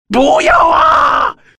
男人竭力大喊不要啊音效免费音频素材下载